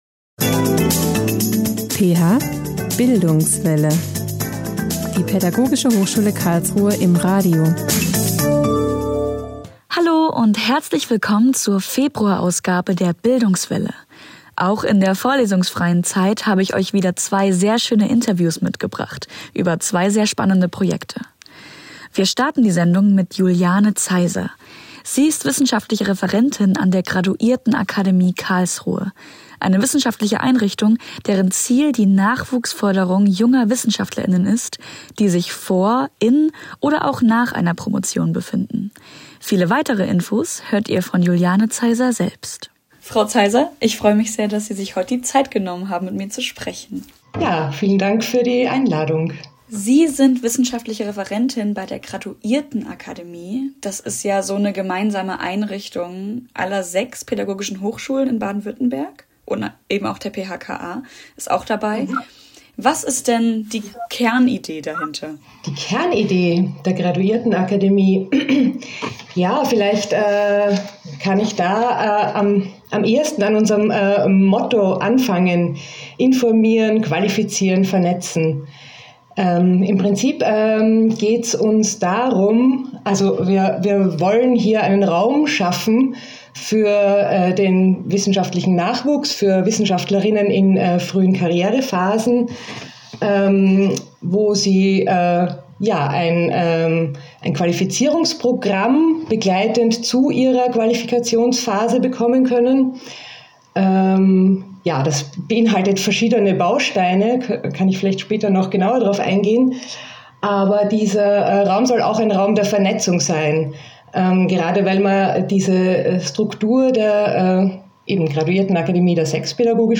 Auch in der vorlesungsfreien Zeit habe ich euch wieder zwei sehr schöne Interviews mitgebracht über zwei sehr spannende Projekte.